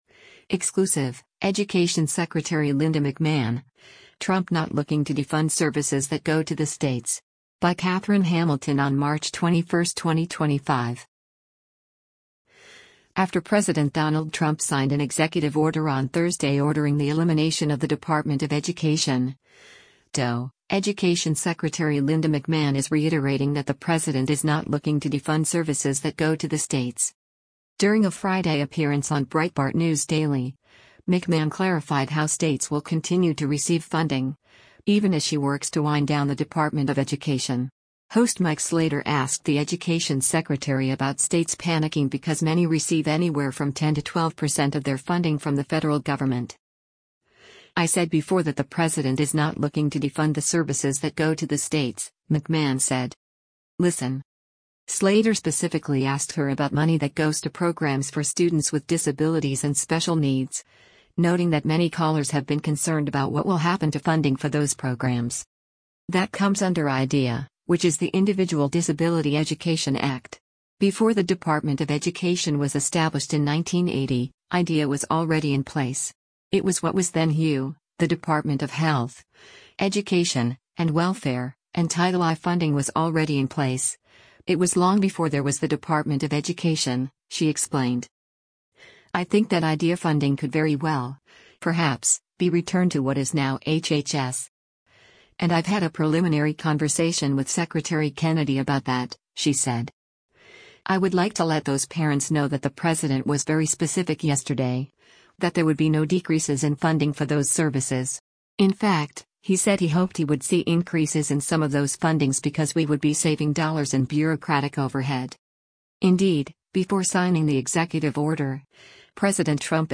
During a Friday appearance on Breitbart News Daily, McMahon clarified how states will continue to receive funding, even as she works to wind down the Department of Education.